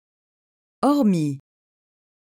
Pronunciation & register
/ɔʁ.mi/